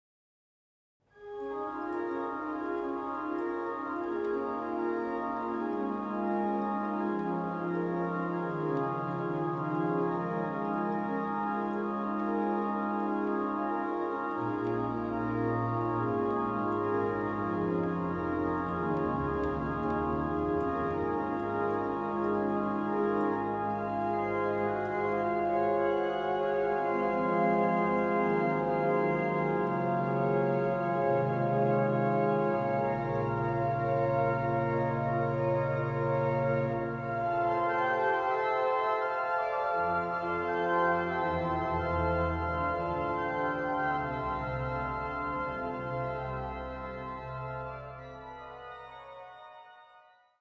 XII/2010 –sv.Jiljí